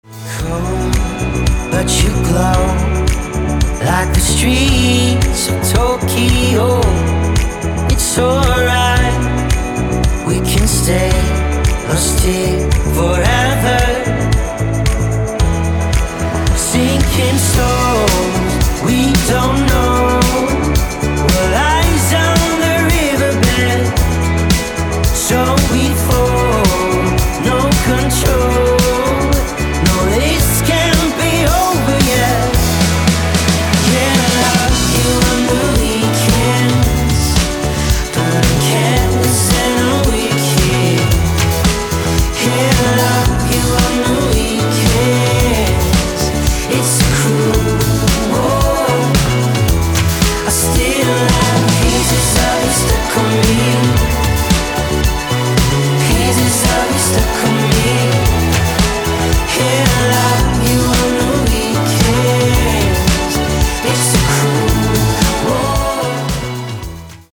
• Качество: 224, Stereo